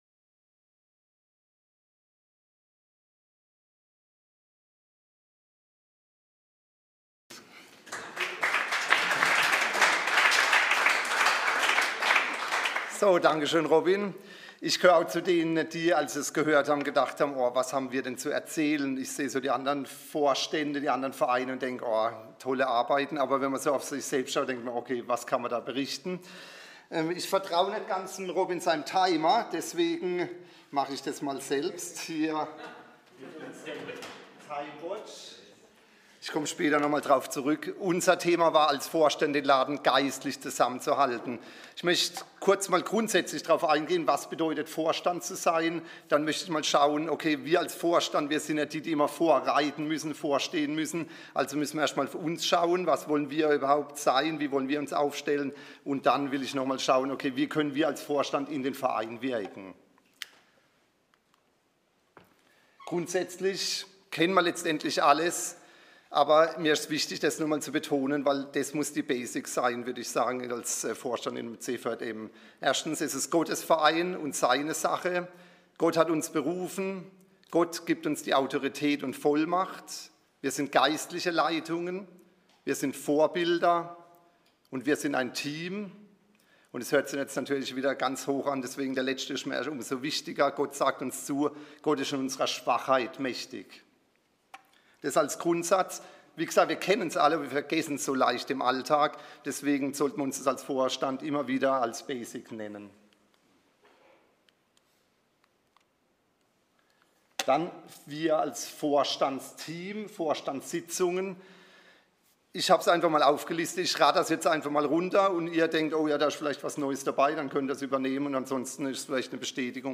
Themenbereich: Vortrag